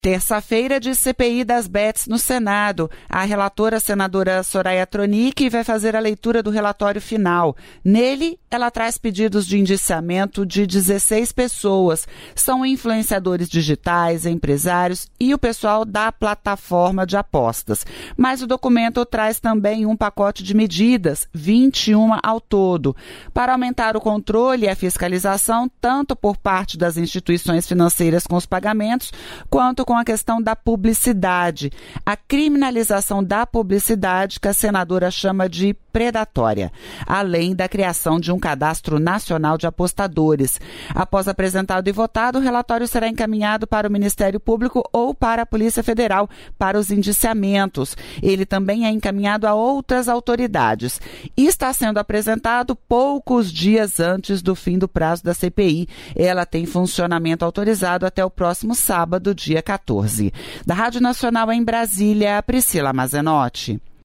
Boletim de notícias veiculado de hora em hora, com duração média de até quatro minutos.
* Este programete foi veiculado às 14h, ao vivo, nas rádios EBC .